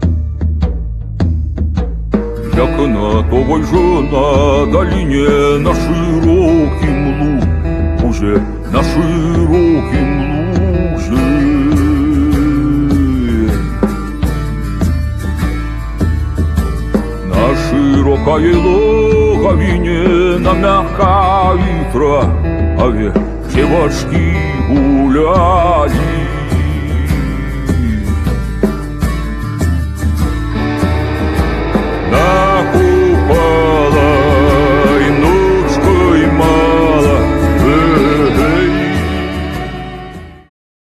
perkusja, darabuka, dżambe, bębny, gong